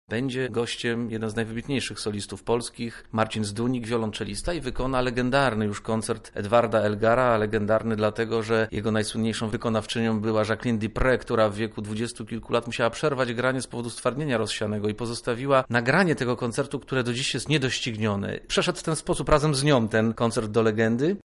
dyrygent.